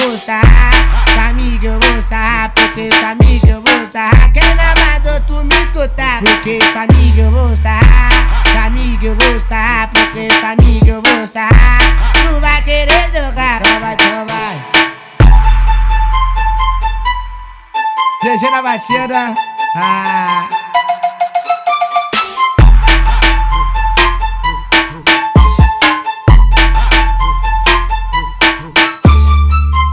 # Brazilian